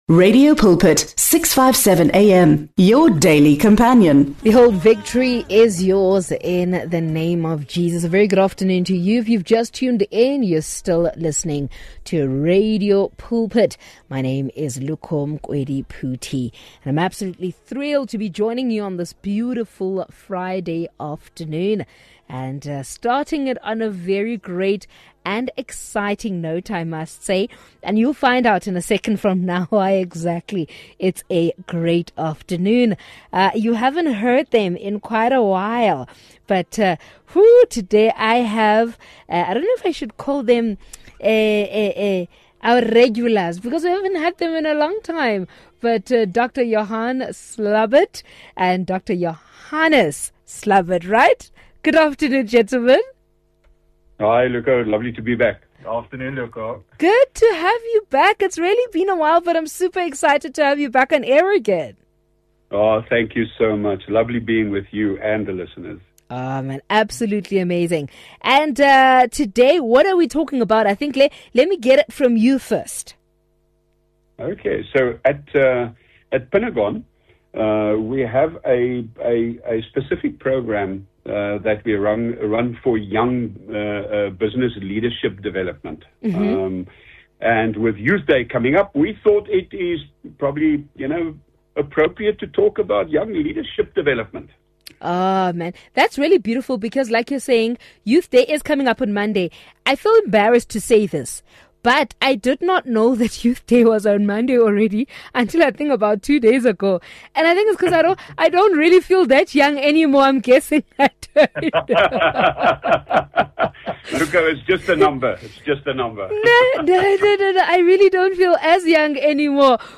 They discuss how the program is designed to cultivate leadership skills, entrepreneurial thinking, and ethical business practices among young professionals. The conversation highlights Pinnagon’s focus on mentorship, practical training, and real-world business challenges, preparing participants for leadership roles.